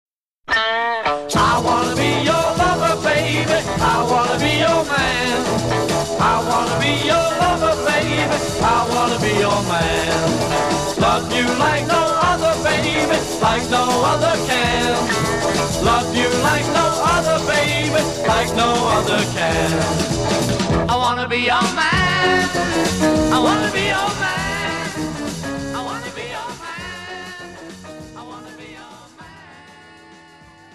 zpěv, bicí
zpěv, kytara
zpěv, basová kytara
sólová kytara
klávesy